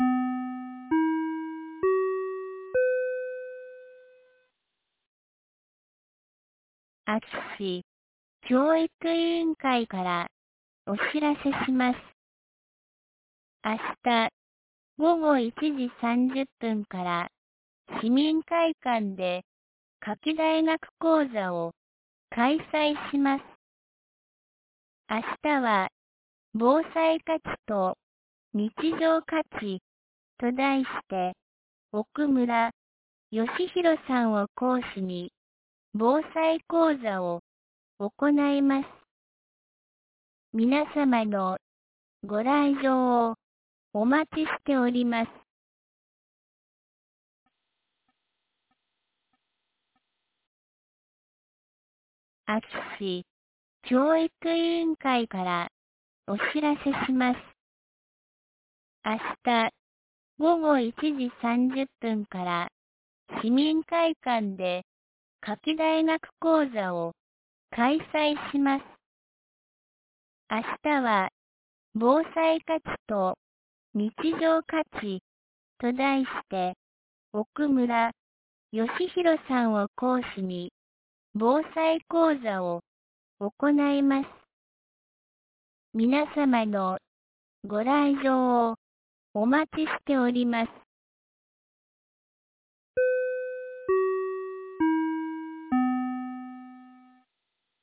2024年08月17日 17時11分に、安芸市より全地区へ放送がありました。